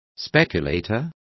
Complete with pronunciation of the translation of speculators.